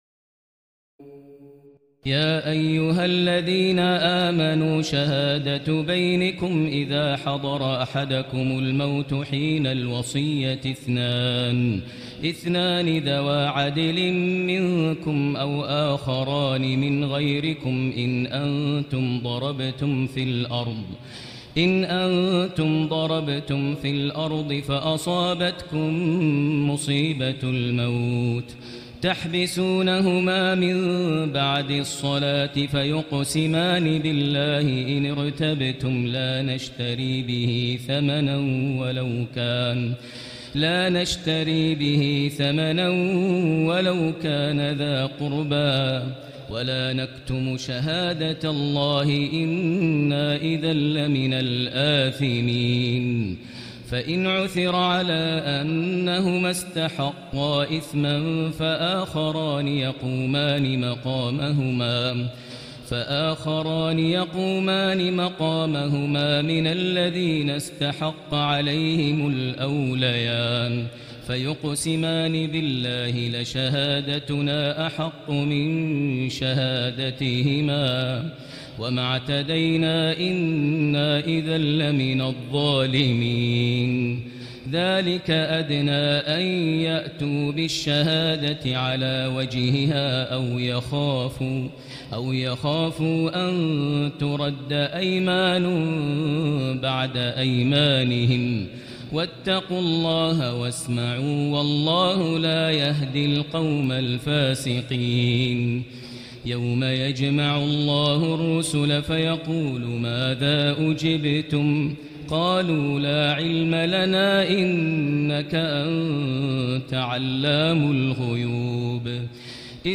تراويح الليلة السادسة رمضان 1439هـ من سورتي المائدة (106-120) و الأنعام (1-73) Taraweeh 6 st night Ramadan 1439H from Surah AlMa'idah and Al-An’aam > تراويح الحرم المكي عام 1439 🕋 > التراويح - تلاوات الحرمين